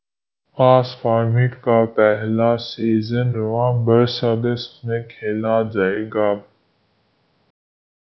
deepfake_detection_dataset_urdu / Spoofed_TTS /Speaker_17 /13.wav